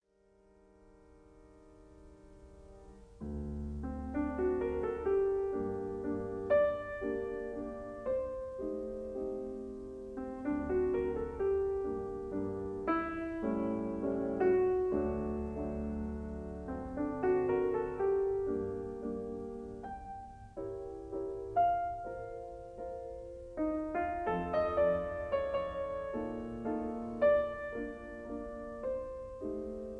piano
1959 stereo recording